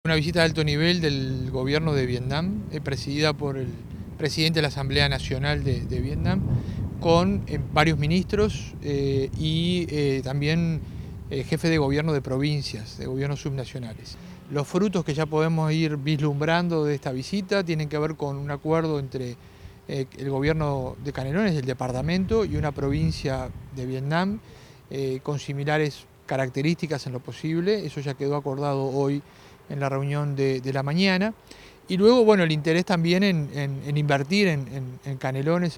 edison_lanza_-_dir._relaciones_internacionales.mp3